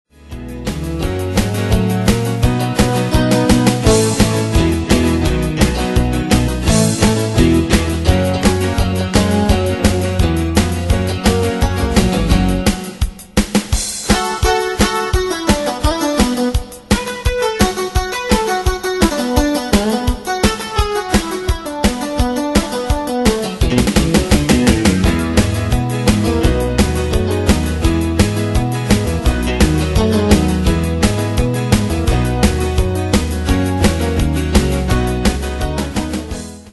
Style: Country Année/Year: 1996 Tempo: 171 Durée/Time: 3.21
Danse/Dance: Rock Cat Id.
Pro Backing Tracks